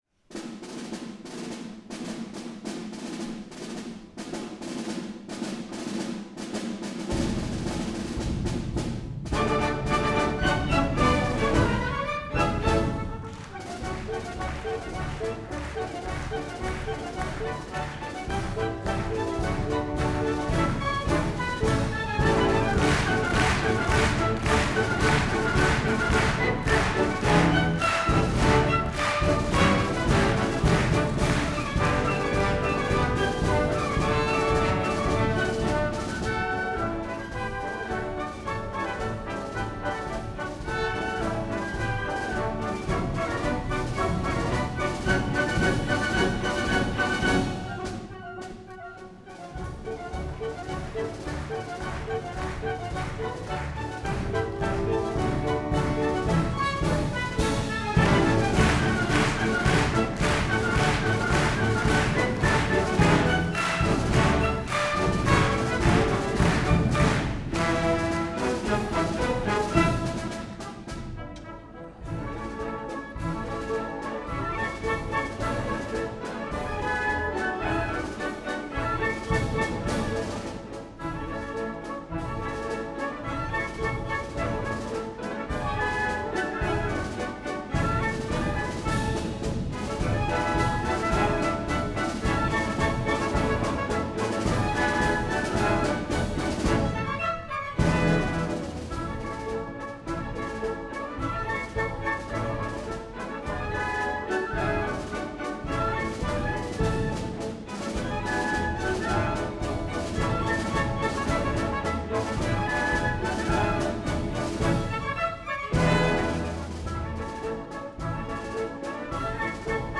Concert du nouvel an 2026
(Wav stéréo 48Khz 24Bits non compressé, nécessite un haut-débit)